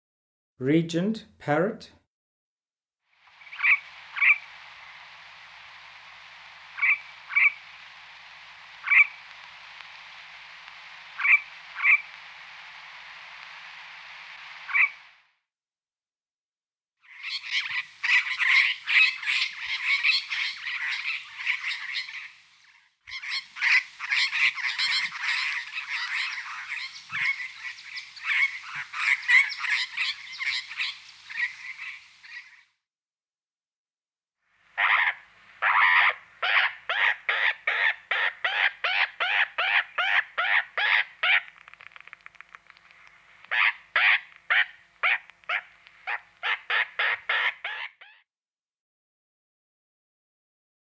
Listen to Regent Parrot Call
regentparrot.mp3